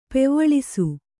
♪ pevvaḷisu